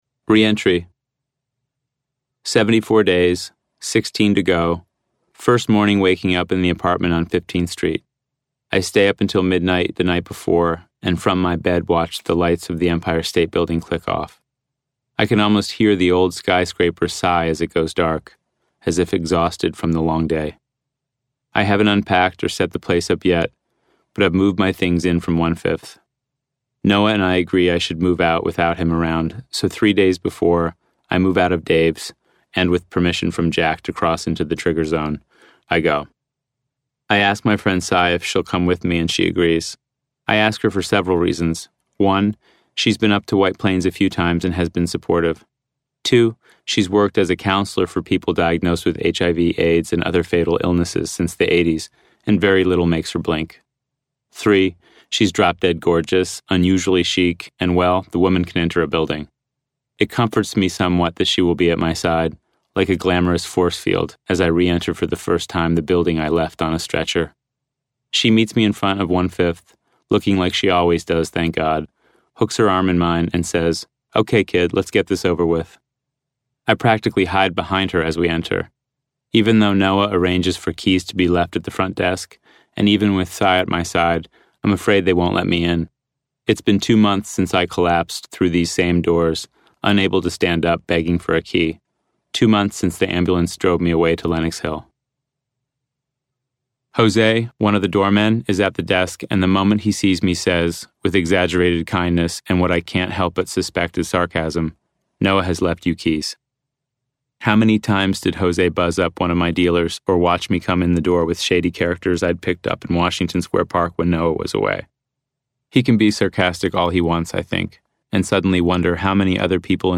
Literary agent and author Bill Clegg reads from his second book, Ninety Days: A Memoir of Recovery, published by Little, Brown, in April, a follow-up to his debut, Portrait of the Addict as a Young Man (Little, Brown, 2010).